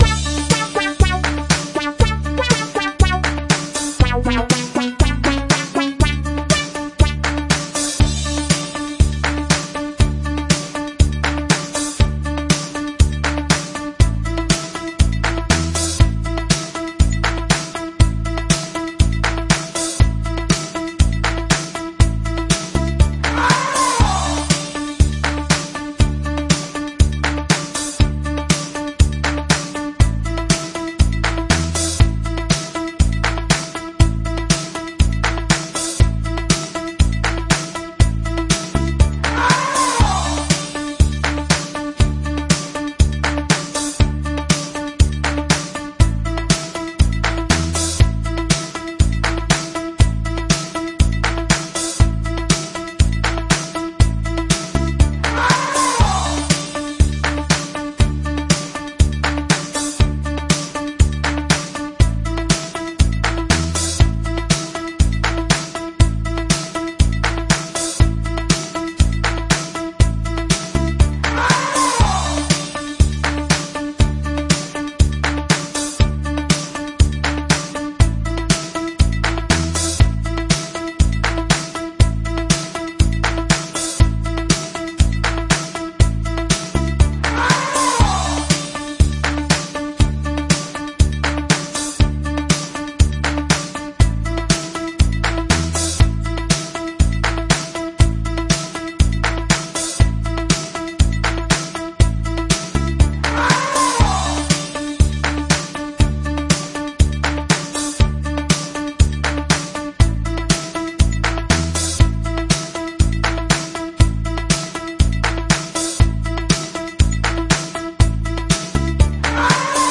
Karaoke Version